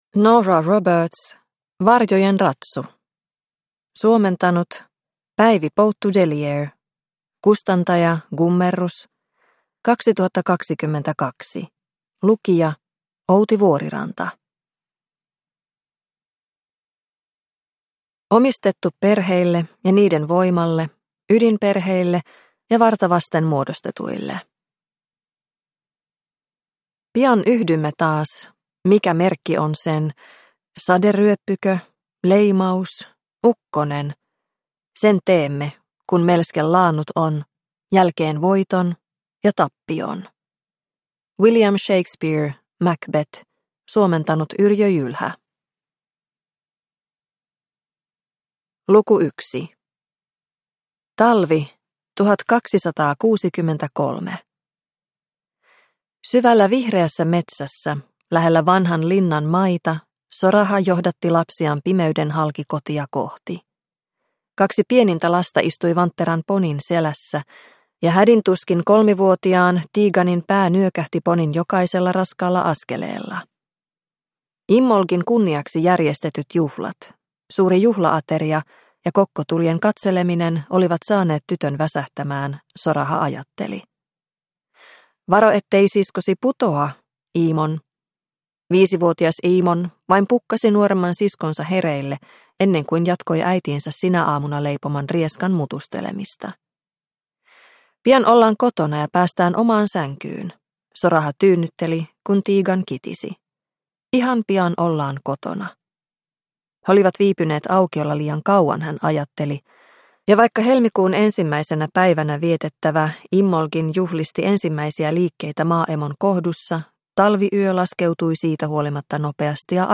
Varjojen ratsu – Ljudbok – Laddas ner